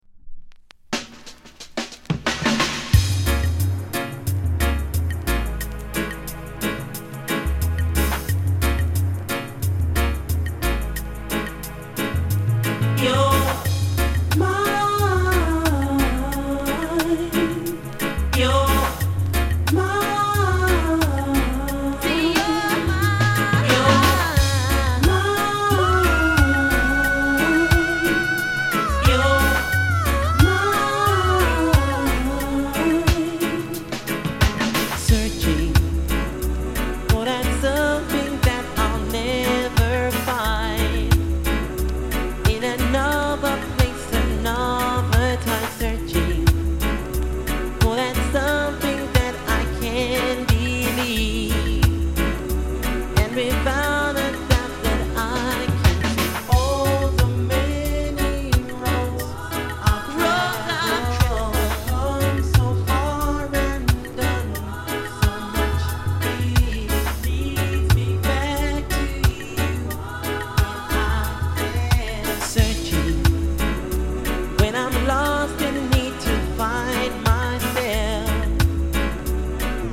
当前位置 > 首页 >音乐 >唱片 >世界音乐 >雷鬼